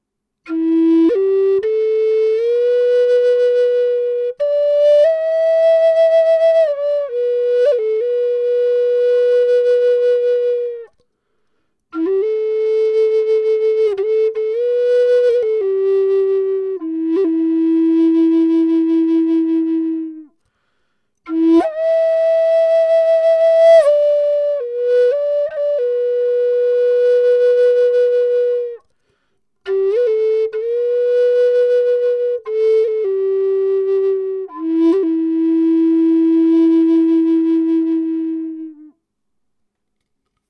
E4 sävellajin Natiivihuilu
Natiiviamerikkalaishuilu E4 sävellajissa. Matala ja lempeä sointitaajuus. Viritetty pentatoniseen molli sävelasteikkoon.
• Vire: Pentatoninen molli (440 hz)
Ääninäyte ilman efektejä (dry):
E4_440hz_pentatonicminor_DRY.mp3